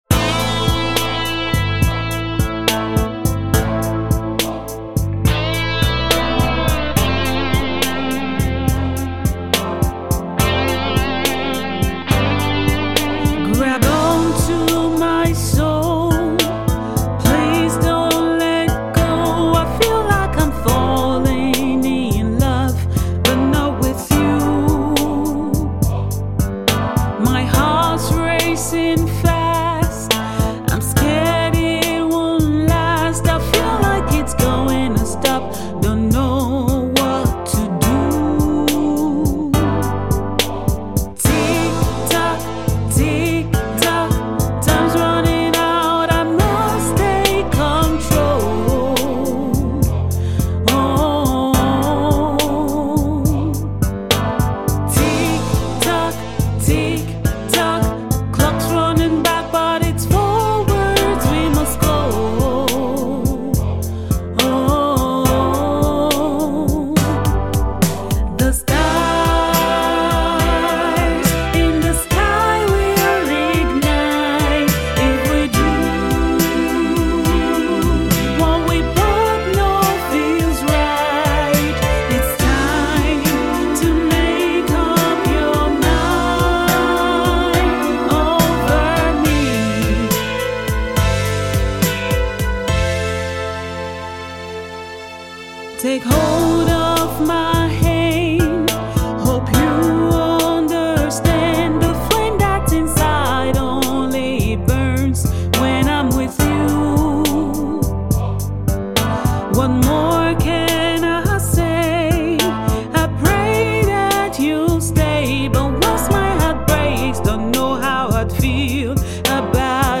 classical and contemporary singer
With a voice that is both powerful and tender